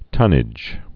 (tŭnĭj)